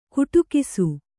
♪ kuṭukisu